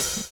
DISCO 9 OH.wav